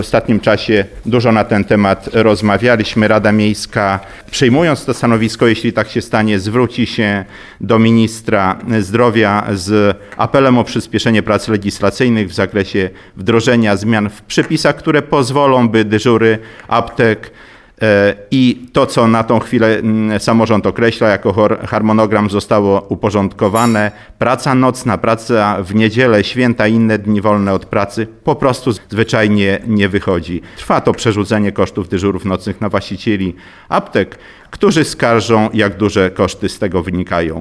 Jak mówi Zdzisław Przełomiec, przewodniczący Rady Miejskiej w Suwałkach, to sprawa ostatnio szeroko dyskutowana.